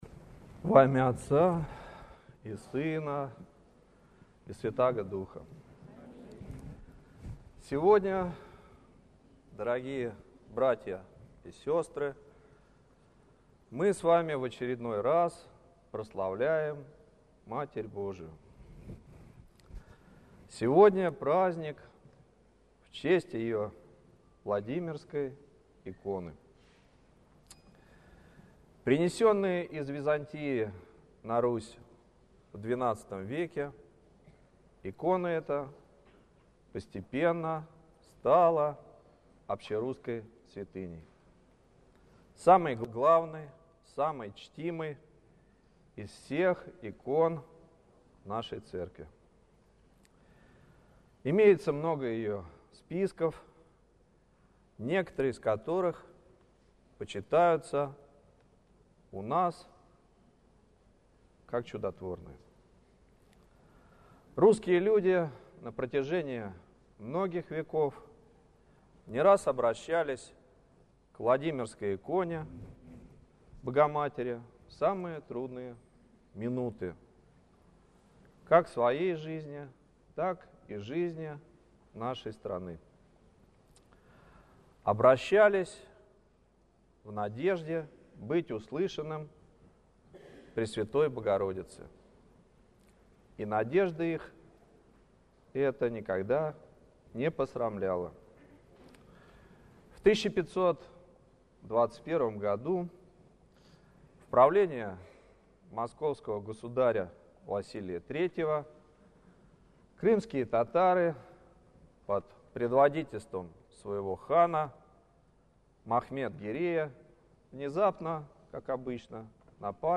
Слово в день праздника Владимирской иконы Божией матери